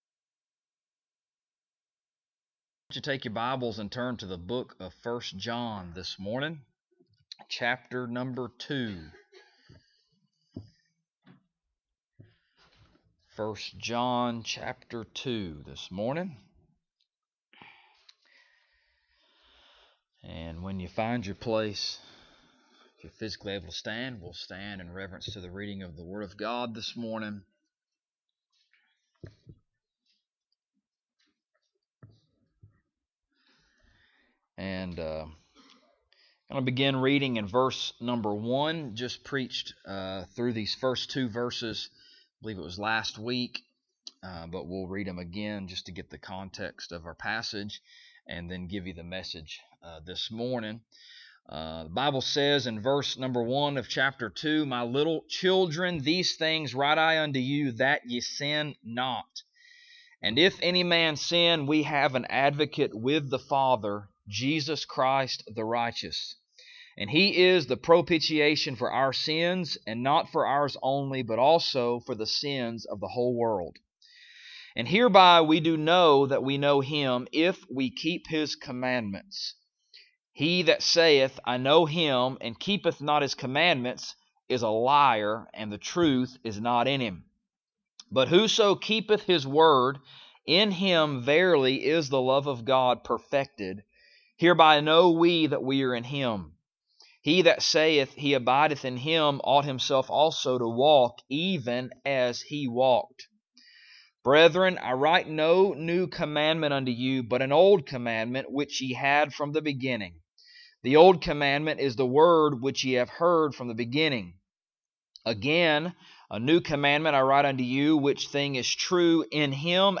1 John Passage: 1 John 2:1-11 Service Type: Sunday Morning « The Main Thing